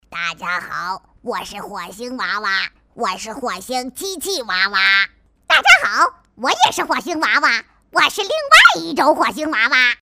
女声配音